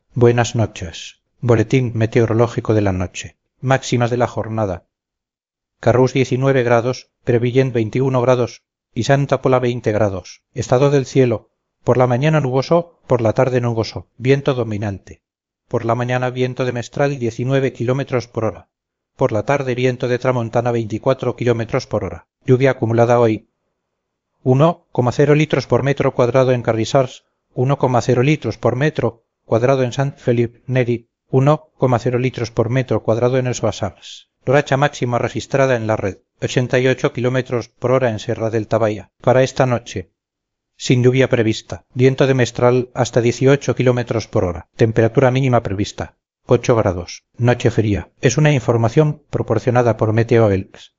Boletín hablado